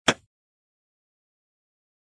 Crossbow Shot SOUND Effect.mp3